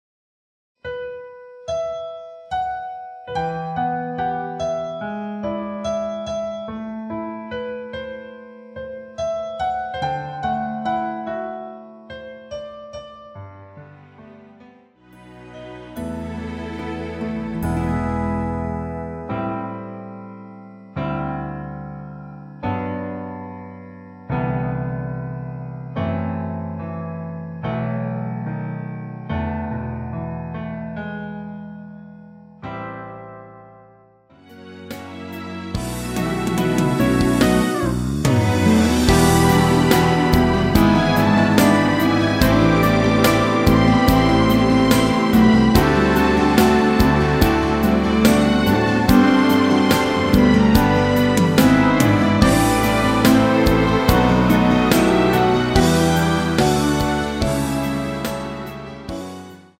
Em
◈ 곡명 옆 (-1)은 반음 내림, (+1)은 반음 올림 입니다.
앞부분30초, 뒷부분30초씩 편집해서 올려 드리고 있습니다.